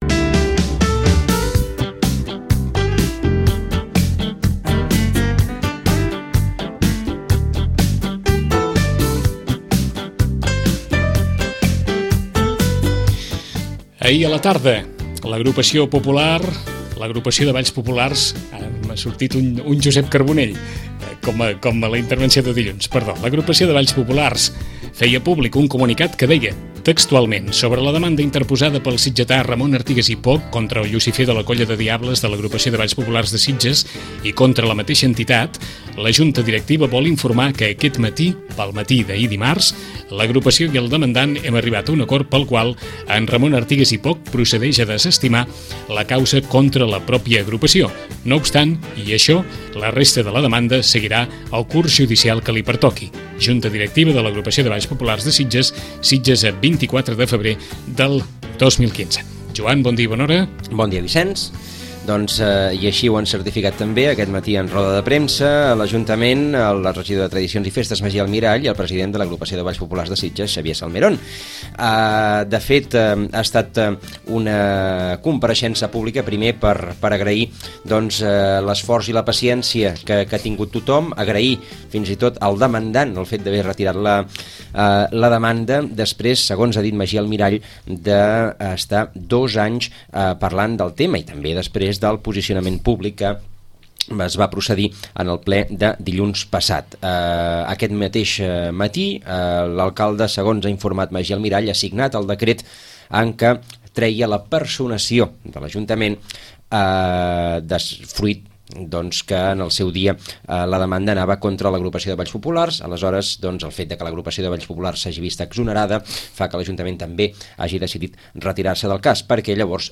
En roda de premsa celebrada aquest matí